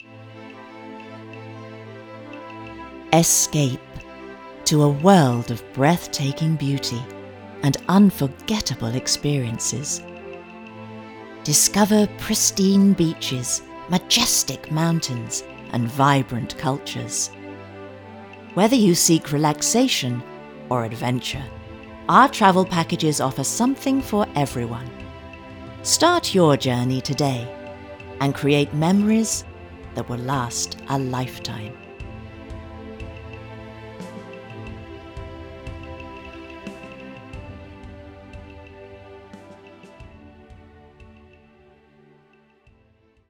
British English Speaker with a wide range of regional and international accents. Young to middle-age.
Sprechprobe: Werbung (Muttersprache):
Spot:Commercial Escape.mp3